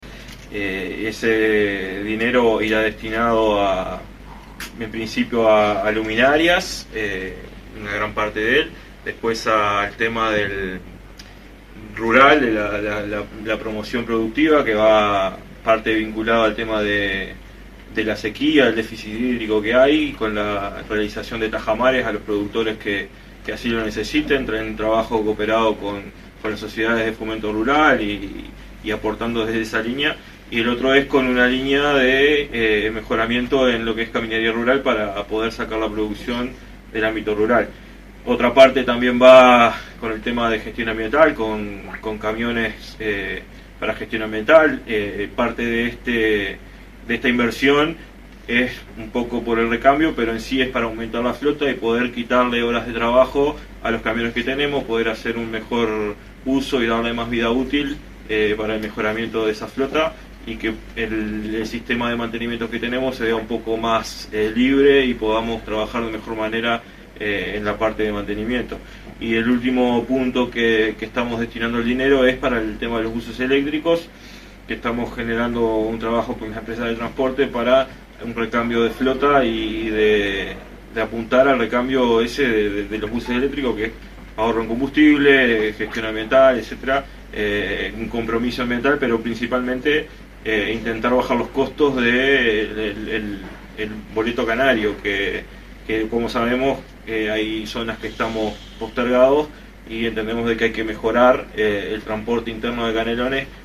Marcelo Ayala, coordinador de bancada del Frente Amplio:
Marcelo-Ayala-edil-FA-coordinador-bancada.mp3